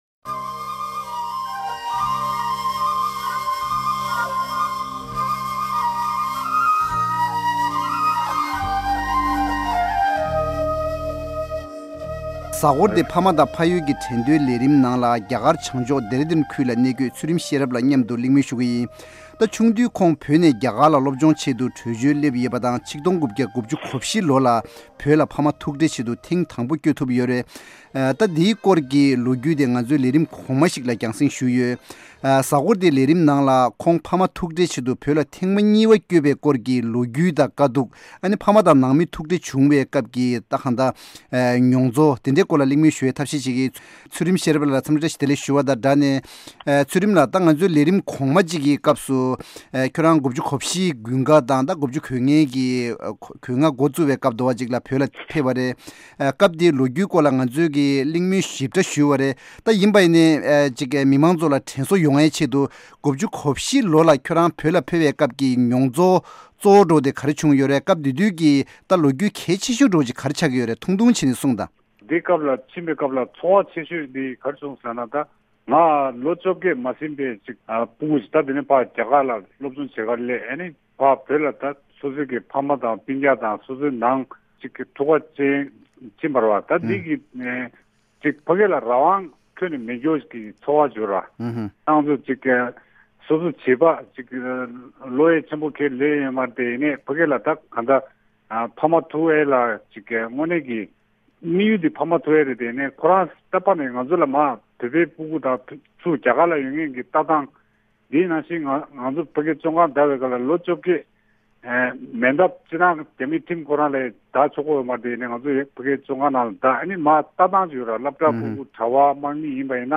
གླེང་མོལ་ཞུས་ཡོད། ༡༩༩༤་ལོར་བོད་ལ་ཕ་མ་ཐུག་འཕྲད་ཆེད་དུ་ཐེངས་དང་པོ་བསྐྱོད་ཐུབ་ཡོད་ཅིང་།